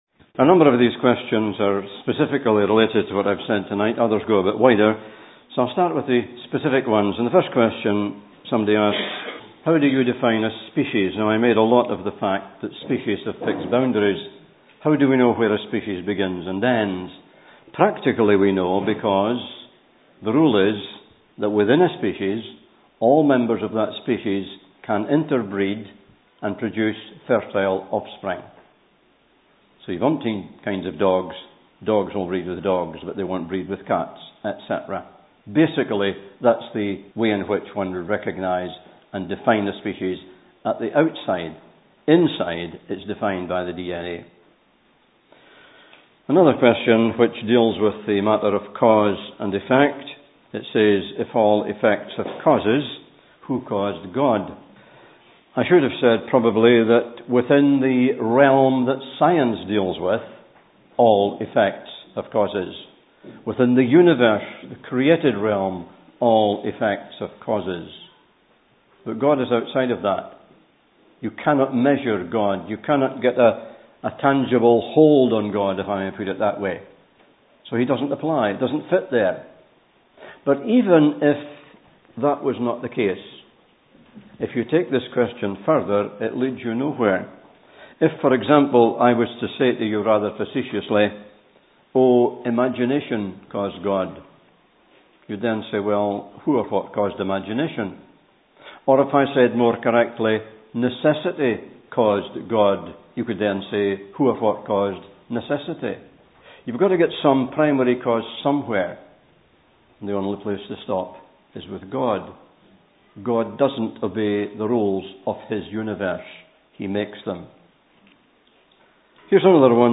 Part 1 – Question Time.